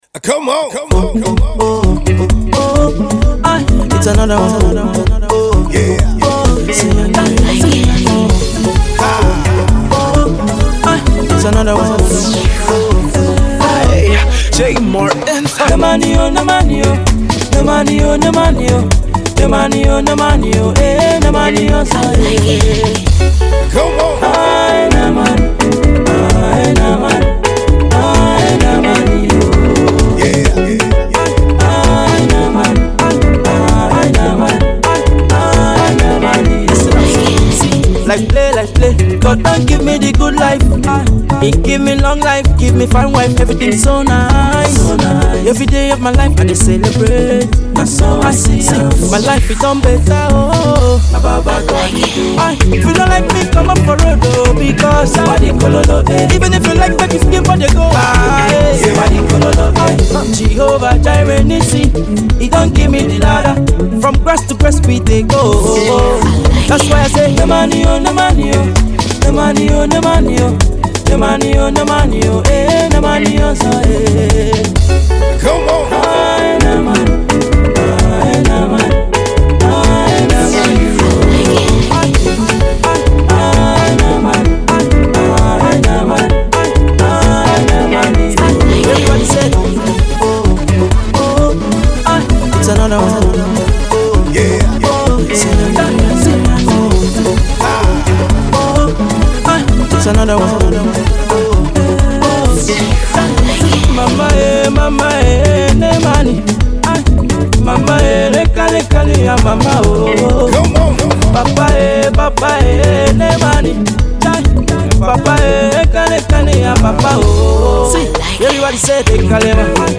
pan African sound